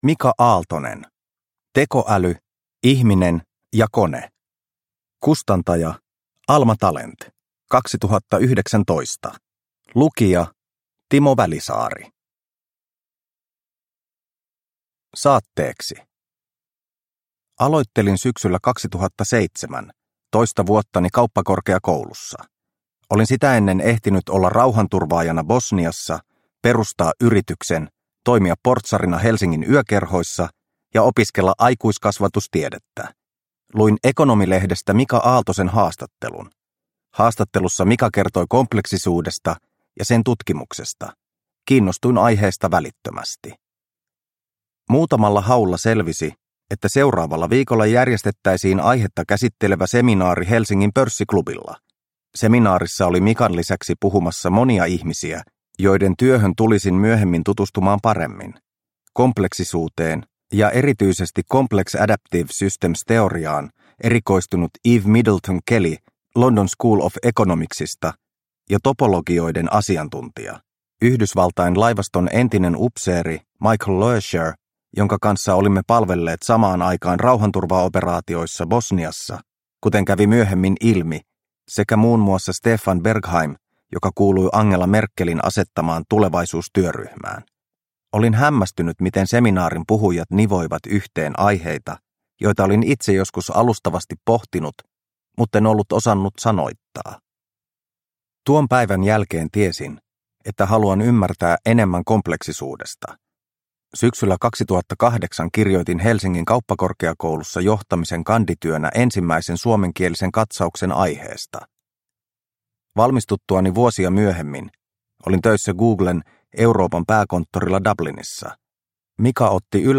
Tekoäly – Ljudbok – Laddas ner